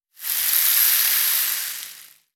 Shaken Rainstick.wav